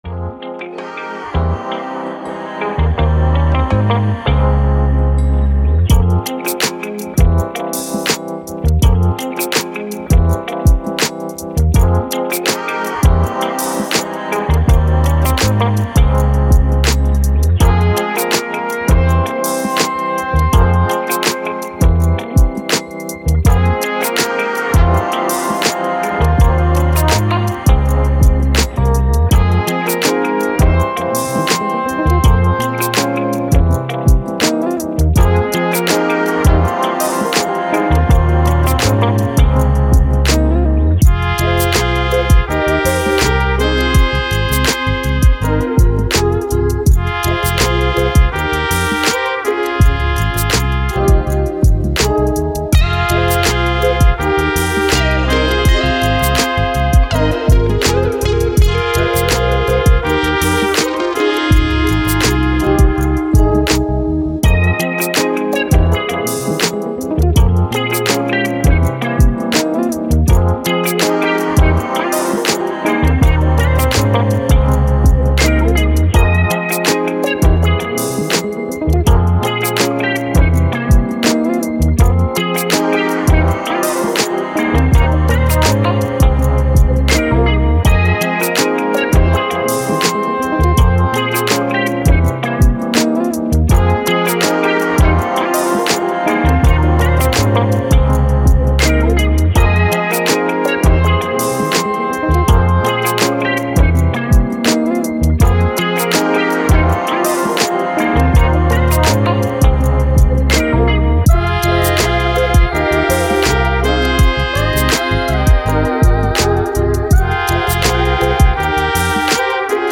Hip Hop, Jazz, Journey, Story